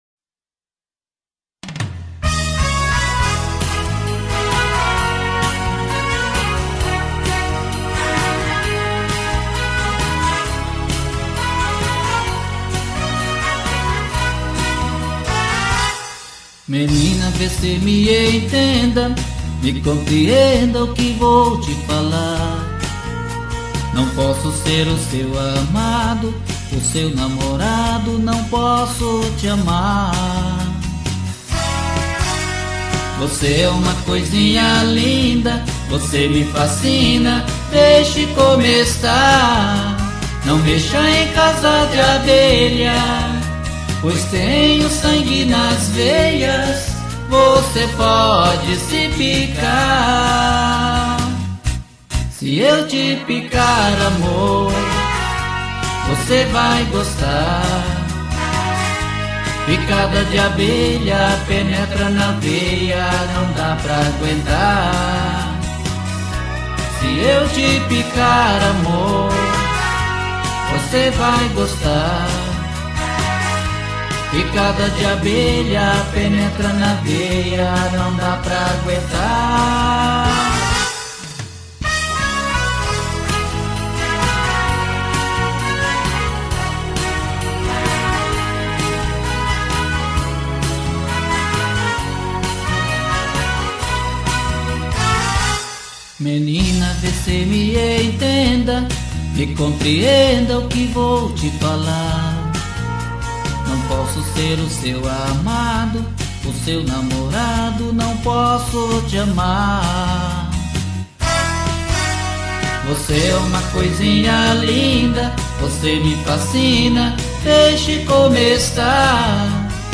Sertanejo.